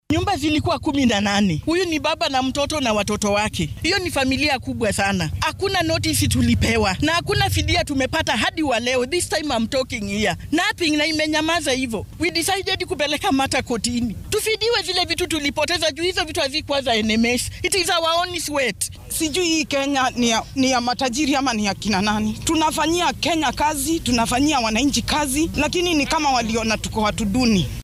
Mid ka mid ah dadka ay arrintan saameysay ayaa dareenkeeda warbaahinta la wadaagtay.